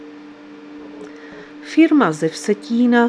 Záznam hlášení místního rozhlasu 13.4.2026